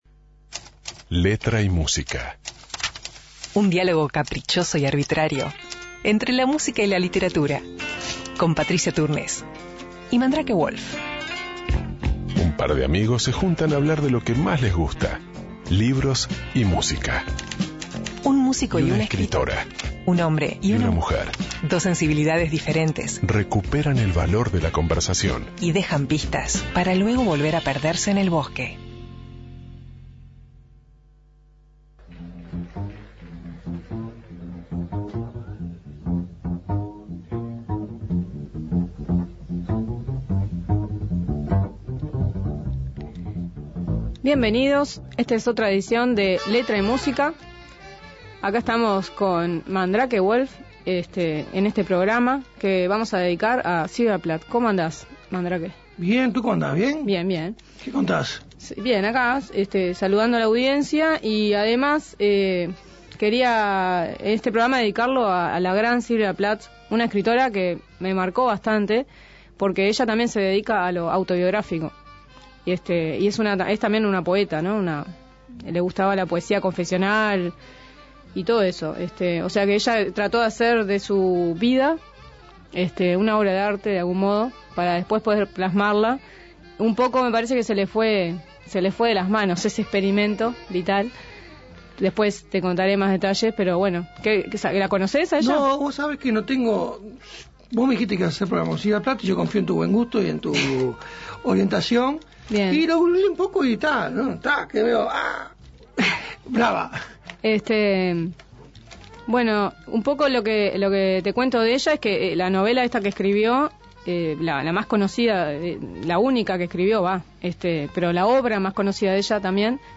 "Leímos fragmentos de "La campana de cristal", la única novela que escribió la escritora y poeta norteamericana Sylvia Plath.